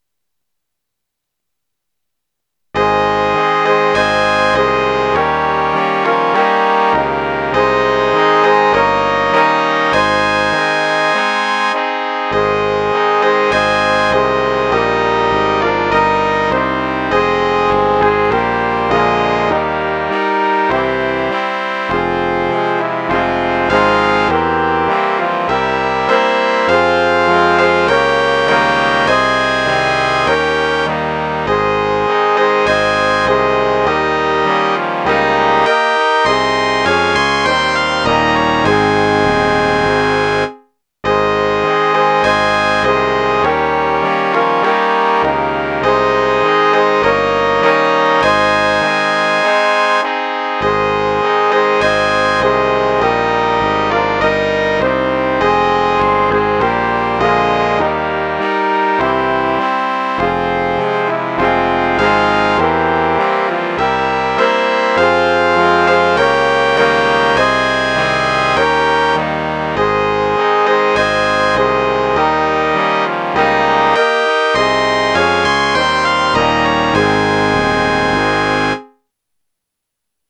School Hymn Check it Out Members of the Graduating Class                         Accompanied by the Band